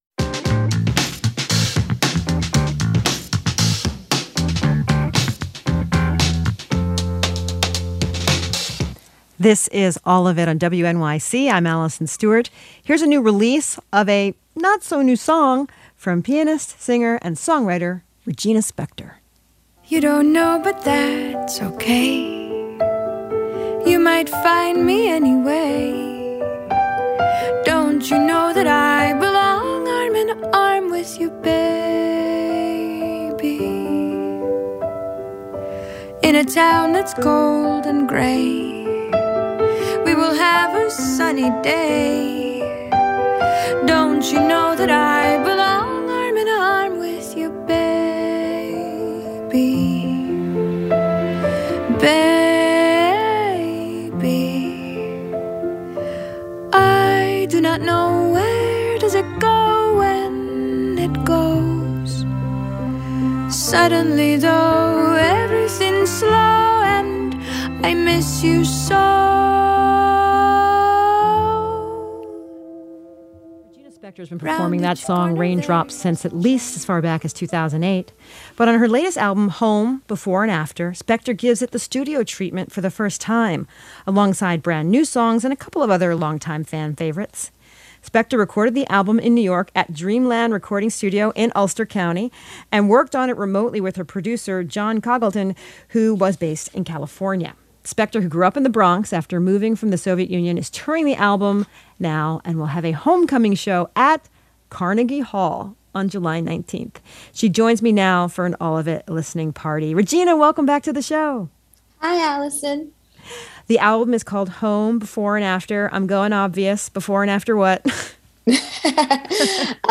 Interviews All of It (WNYC) July 13, 2022 Mail Mail Description: Regina Spektor discusses her latest album, Home, before and after, reflecting on how some of the songs have been with her for years before finally getting a studio release.